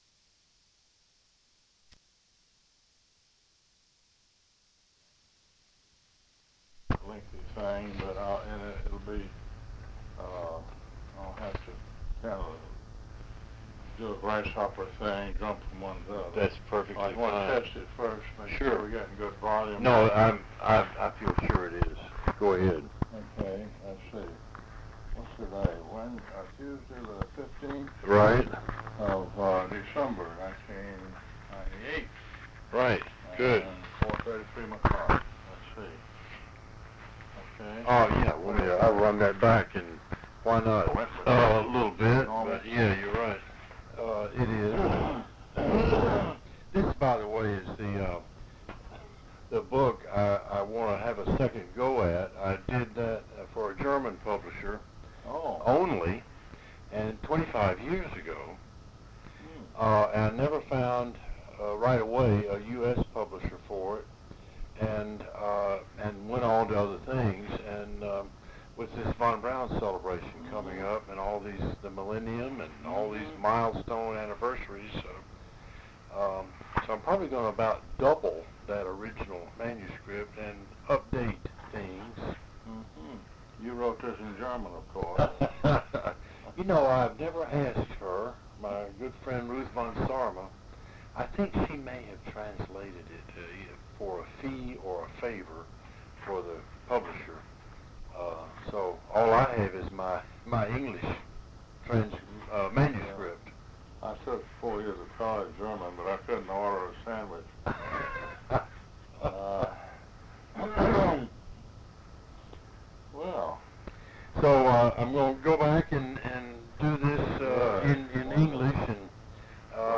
Both sides of tape.
Interviews
Audiocassettes
Oral History